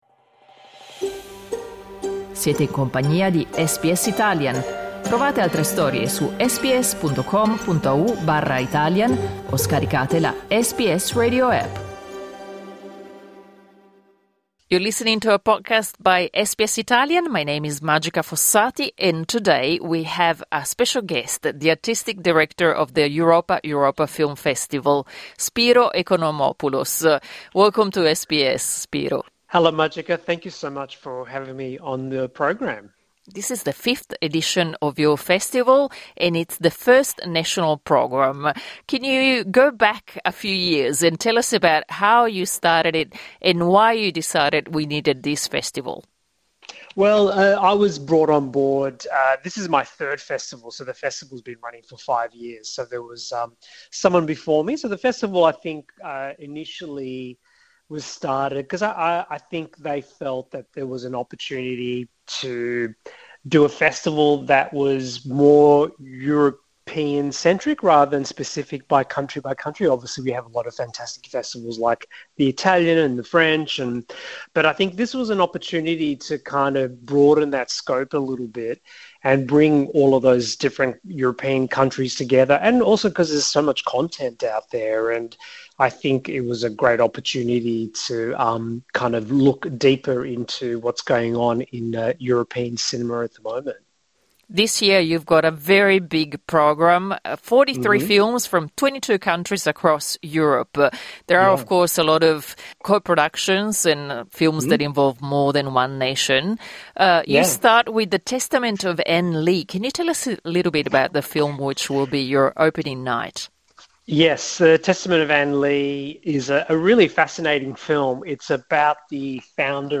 Clicca sul tasto "play" in alto per ascoltare l'intervista in inglese Nel fitto programma dell' Europa!